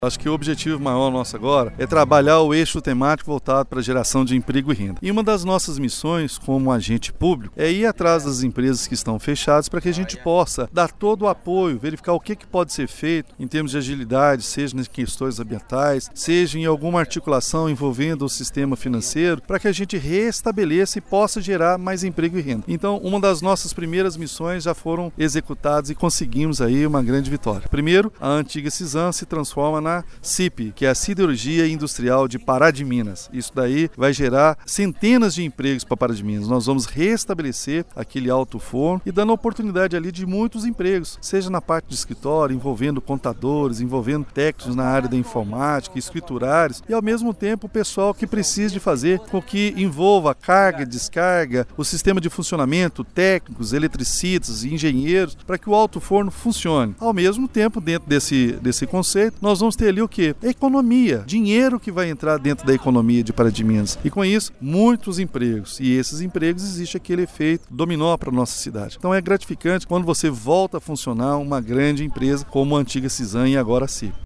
A informação foi confirmada pelo prefeito Elias Diniz (PSD).
Ele anuncia que uma indústria metalúrgica que estava fechada há alguns anos voltará a funcionar com um novo nome, gerando emprego e renda para o município de Pará de Minas: